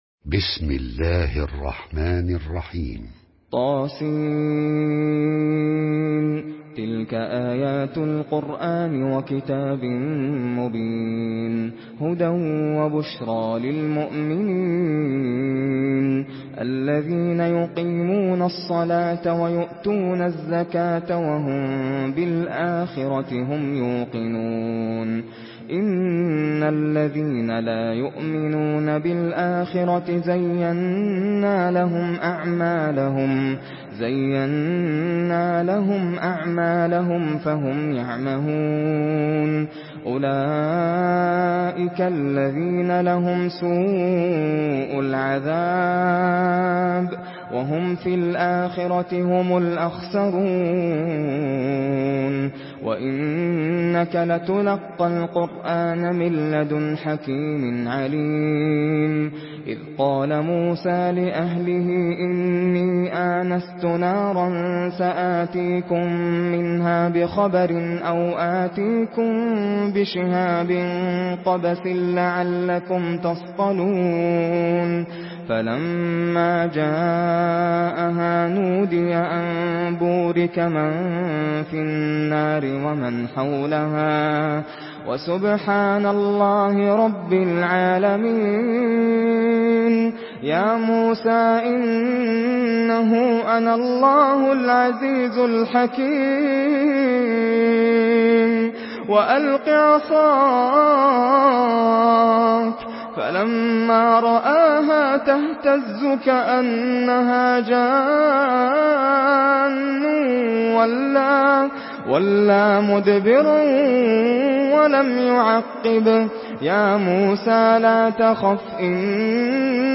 Surah An-Naml MP3 by Nasser Al Qatami in Hafs An Asim narration.
Murattal Hafs An Asim